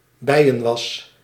Ääntäminen
IPA : /ˈbizwæks/